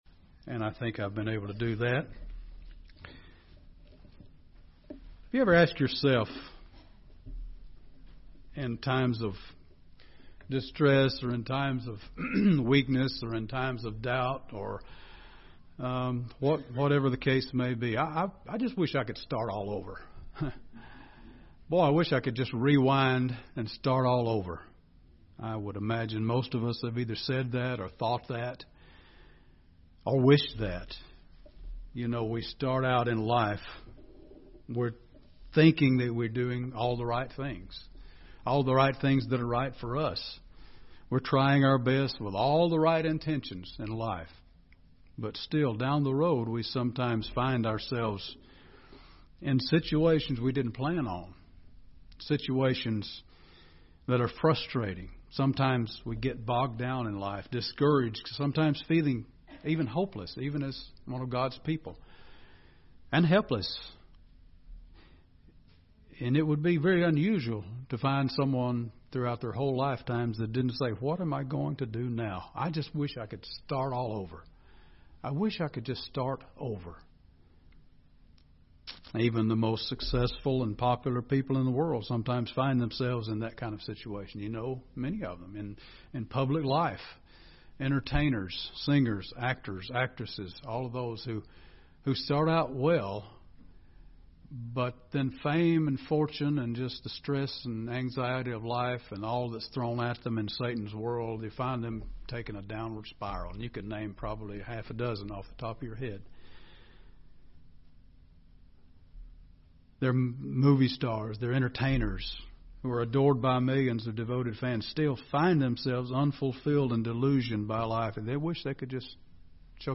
Given in Gadsden, AL
UCG Sermon Studying the bible?